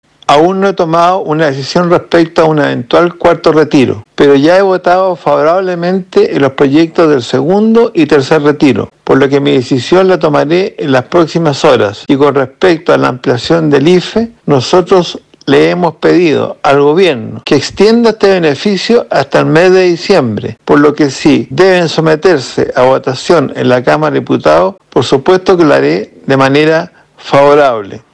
El parlamentario de la Unión Demócrata Independiente, Diputado Gastón Von Mühlenbrock, aseveró que en su momento determinará una postura sobre el cuarto retiro de los fondos desde las AFP, aclarando que la segunda y tercera iniciativa tuvo su apoyo.